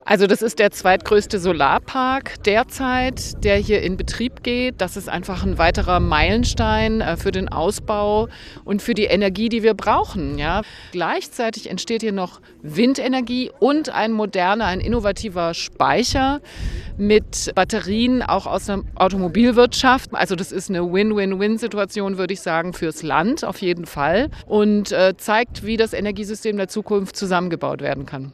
Thekla Walker, Ministerin für Umwelt, Klima und Energiewirtschaft Baden-Württemberg